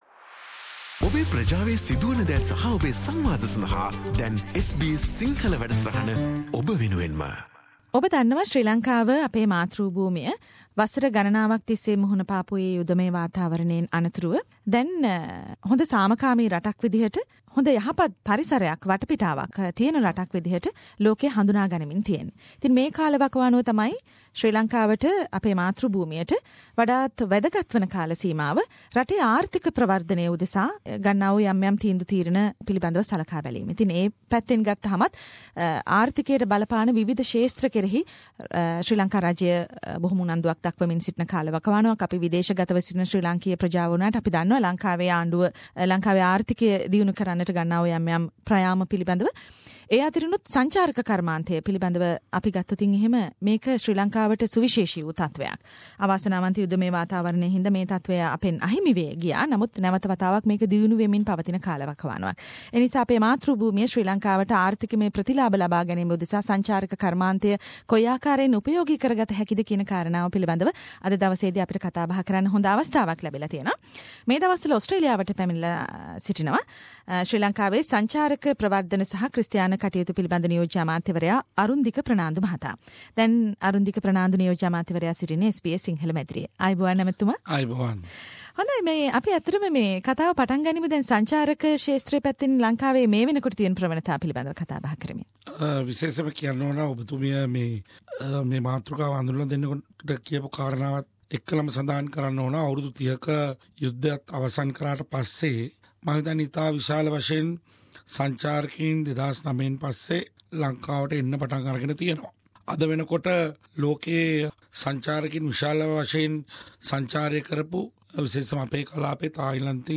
Deputy Minister to the Ministry of Sri Lankan Tourism Mr: Arundika Fernando visited SBS Sinhalese radio during his Melbourne visit to talk about the trends and opportunities in the tourism field in Sri Lanka. The interview